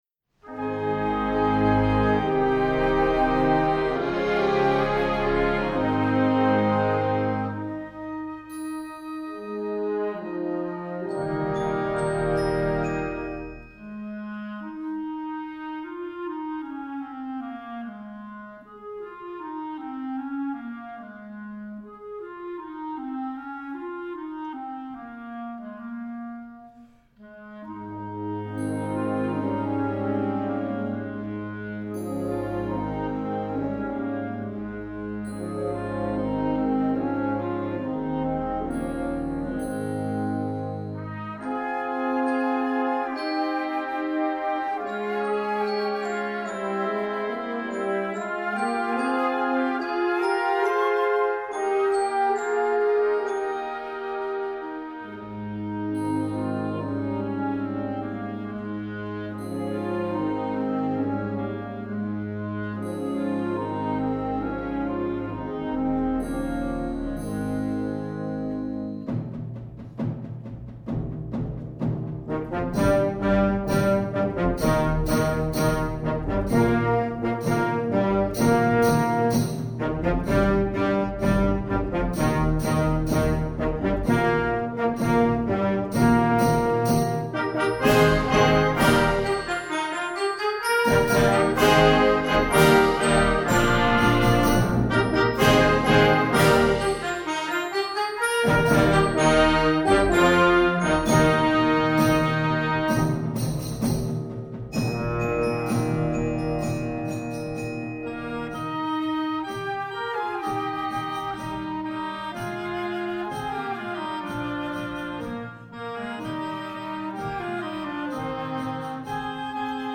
Besetzung: Blasorchester
The lyrical
and the joyous